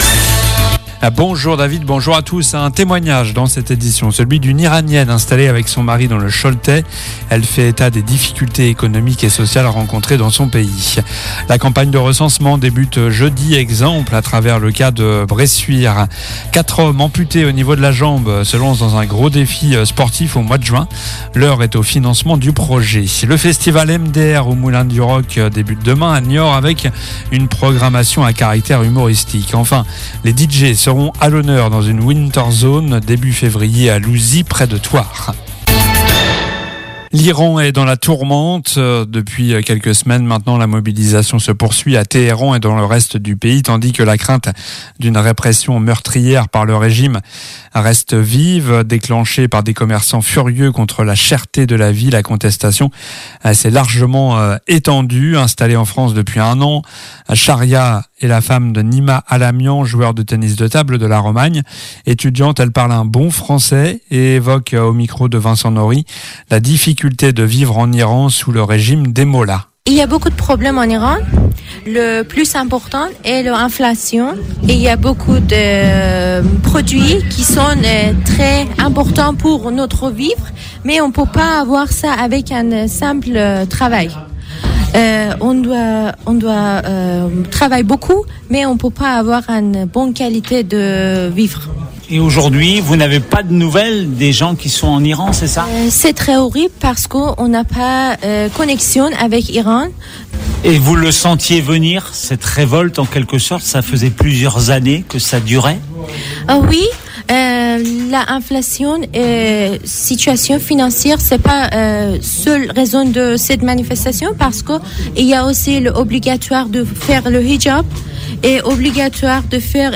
JOURNAL DU MARDI 13 JANVIER ( MIDI )